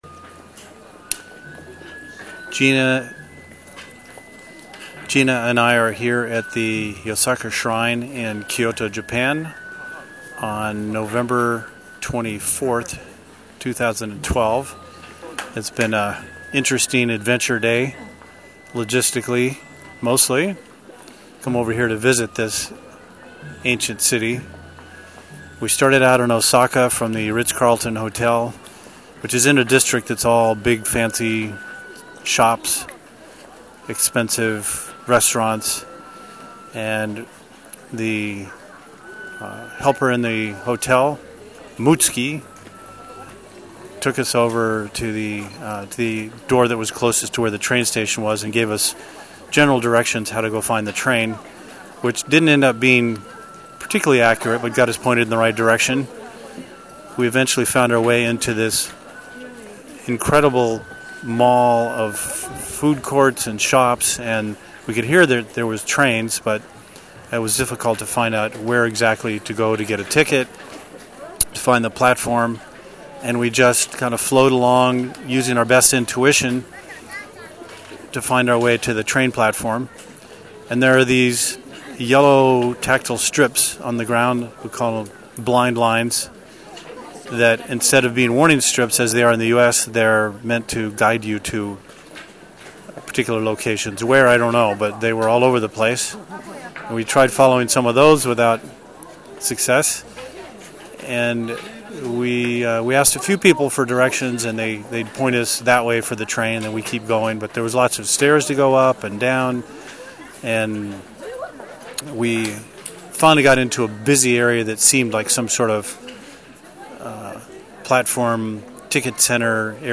audio file from the Yasaka Shrine.